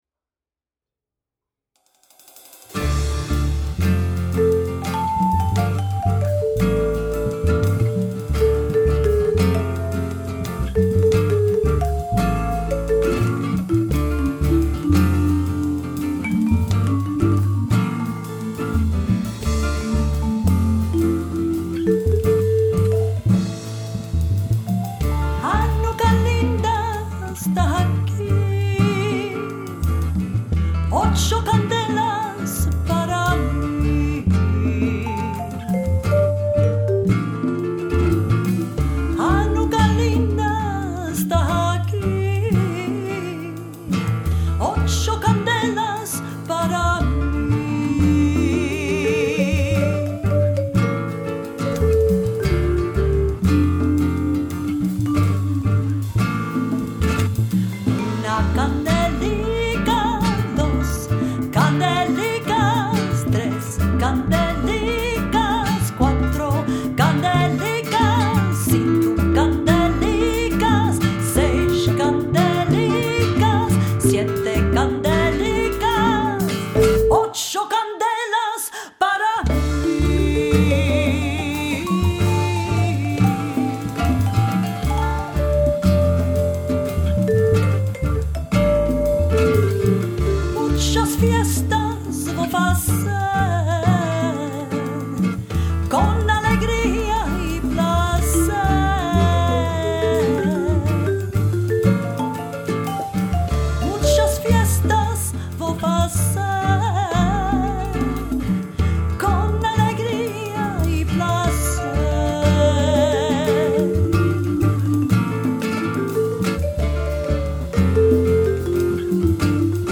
Latin Jazz band